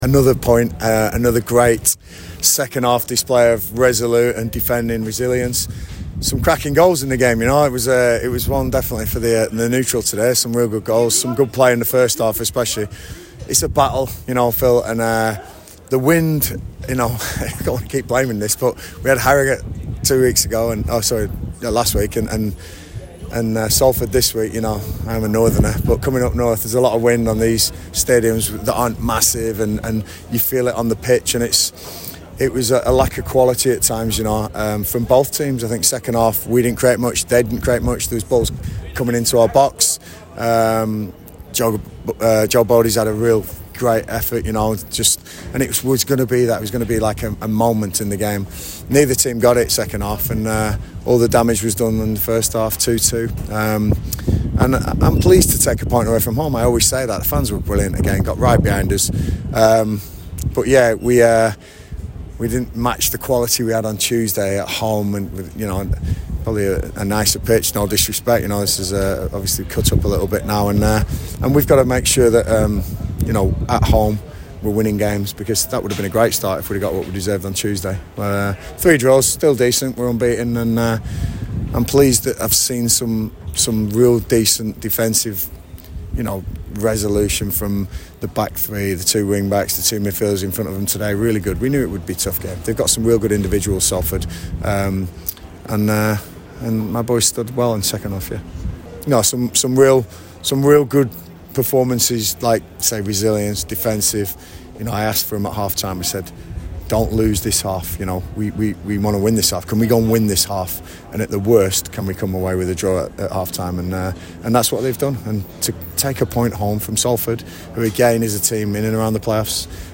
LISTEN: Gillingham manager Gareth Ainsworth speaks after their 2 -2 draw with Salford City